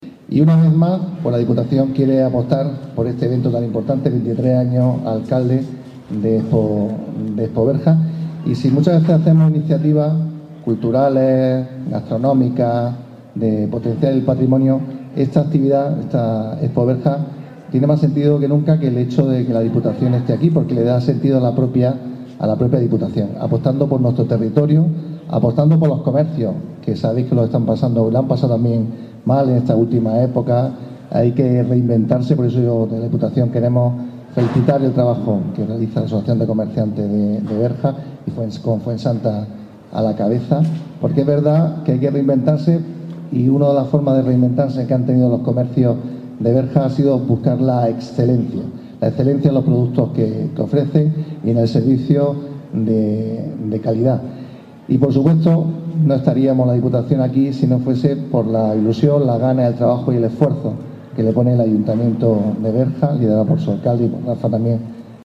Así, con el corte de la cinta inaugural el jueves por la noche se dio inicio a una nueva edición de ExpoBerja 2023.